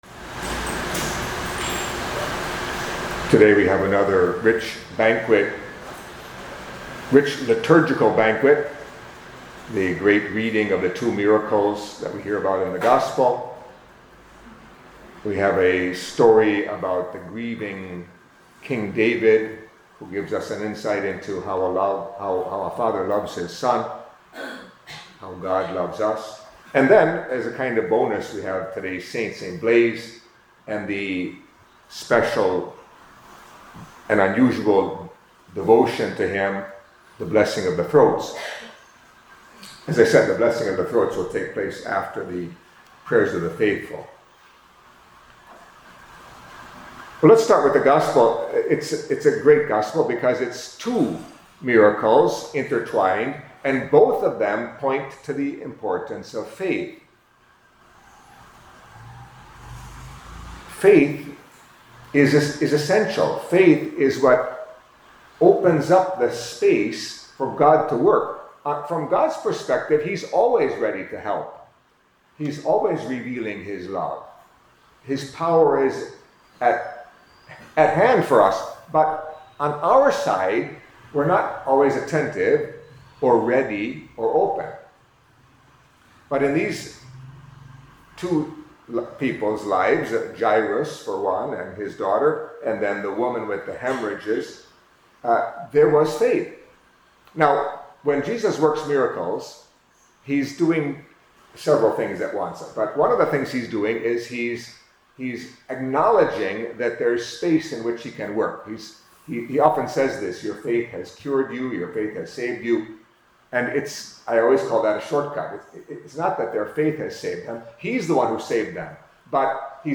Catholic Mass homily for Tuesday of the Fourth Week in Ordinary Time